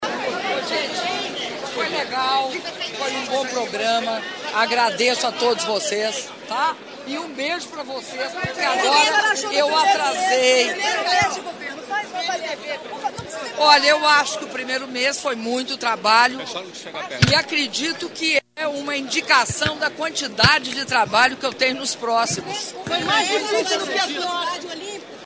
Áudio da entrevista coletiva concedida pela Presidenta da República, Dilma Rousseff, após solenidade de anúncio de medicamentos gratuitos para hipertensão e diabetes no programa ''Aqui tem Farmácia Popular'' (25s)